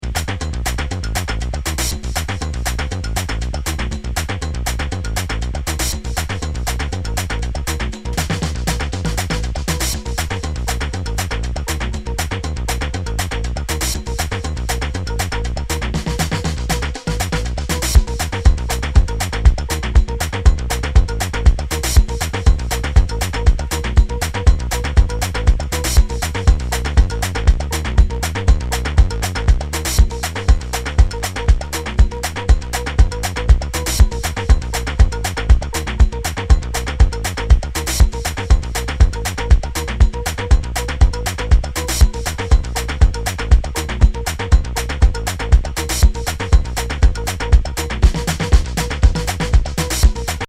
ブライトンのディスコ・ディガー集団の’09年作。